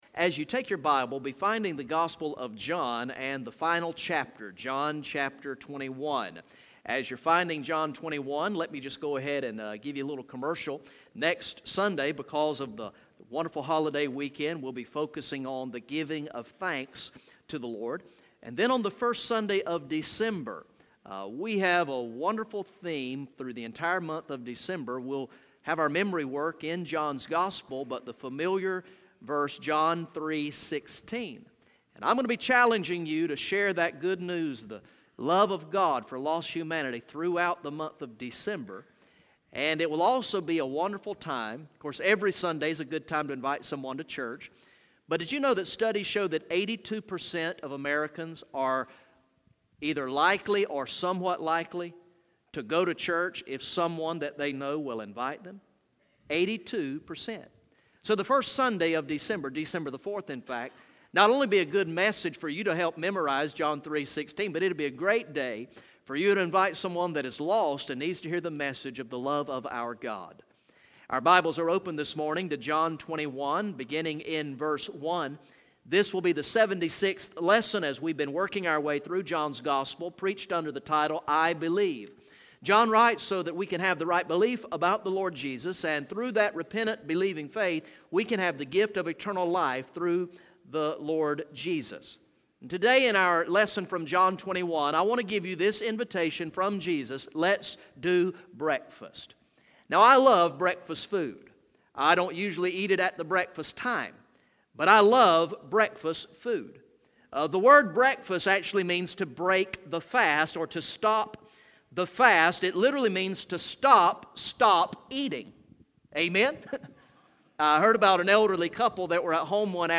Message #75 from the sermon series through the gospel of John entitled "I Believe" Recorded in the morning worship service on Sunday, November 20, 2016